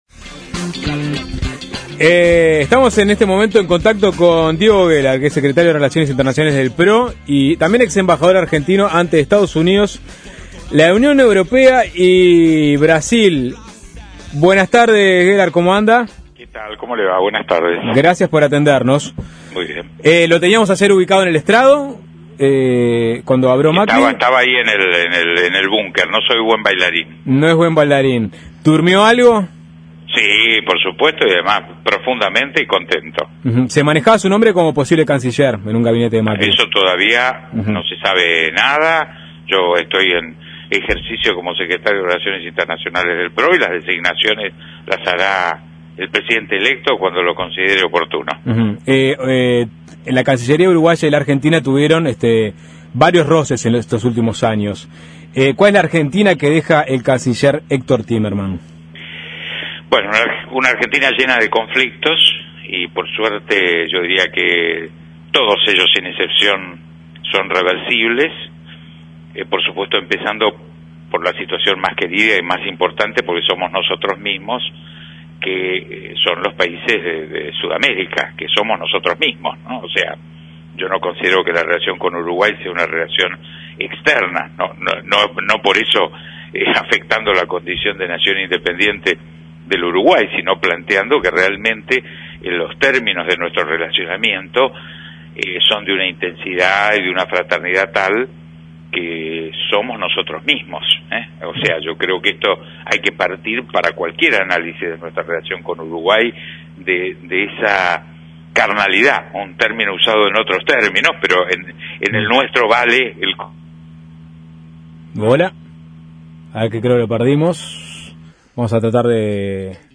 Suena Tremendo habló con Diego Guelar, secretario de Relaciones Internacionales del PRO, quien emitió su opinión acerca de cómo debe enfrentar Argentina su situación económica actual y la continuidad de las políticas sociales. También expresó su visión de la relación con Uruguay y Venezuela.